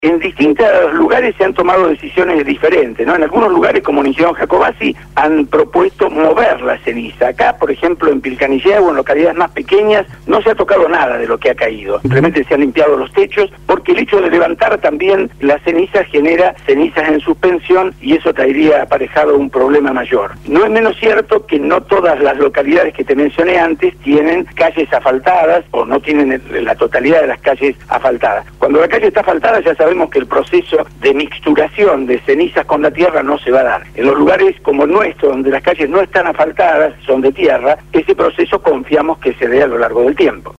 INFORME DESDE LA PATAGONIA: LA ERUPCIÓN DEL VOLCÁN PUYEHUE EN CHILE